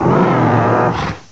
cry_not_mudsdale.aif